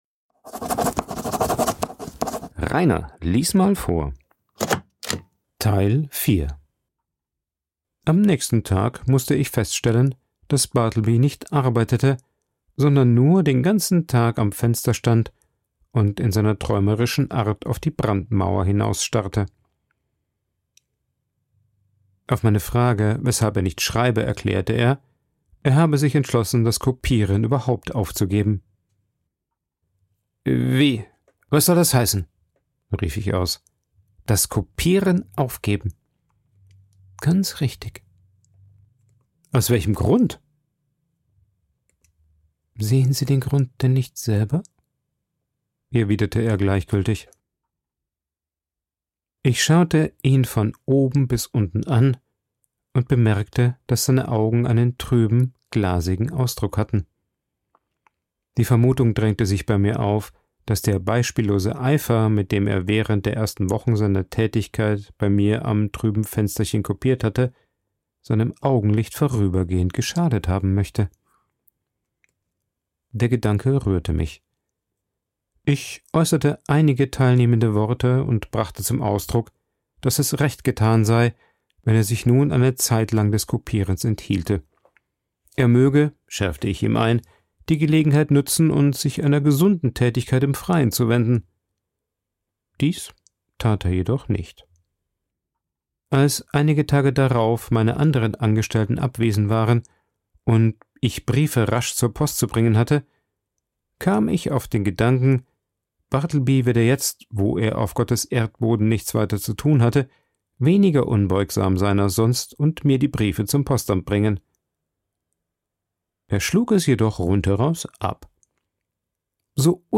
Vorgelesen
aufgenommen und bearbeitet im Coworking Space Rayaworx, Santanyí, Mallorca.